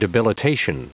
Prononciation du mot debilitation en anglais (fichier audio)
Prononciation du mot : debilitation